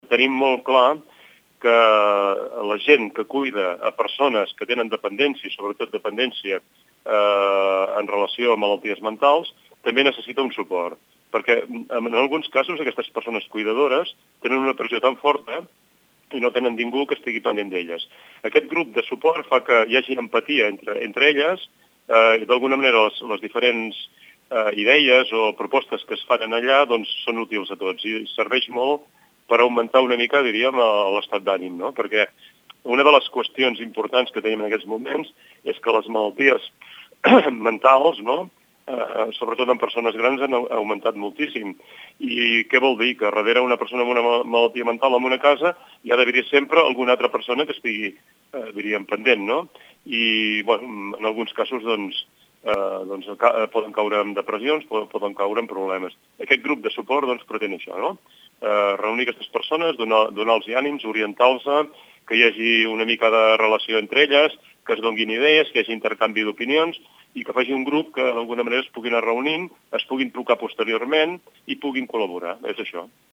Àngel Pous és regidor de serveis socials.